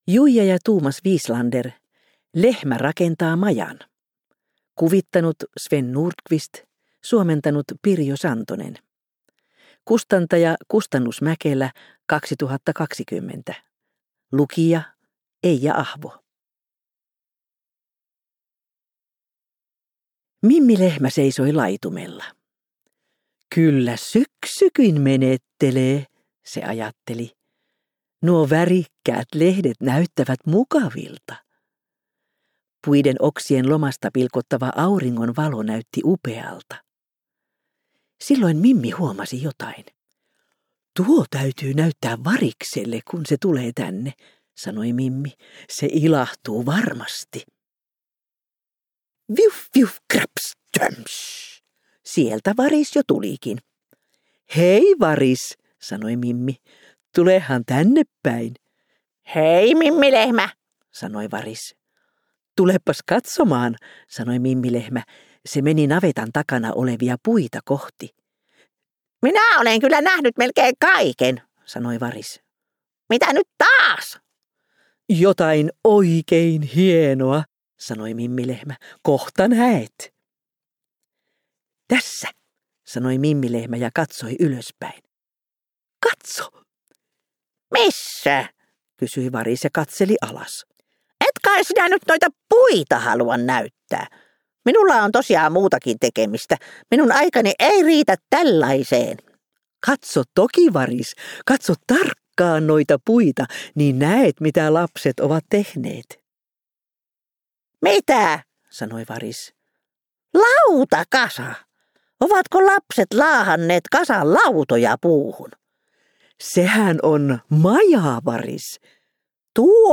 Äänikirja on kuunneltavissa useissa eri äänikirjapalveluissa, lukijana Eija Ahvo.